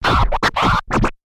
DJ Scratching a record
Abrasive Chopped Club Crisp Cutting Distorted DJ Dynamic sound effect free sound royalty free Music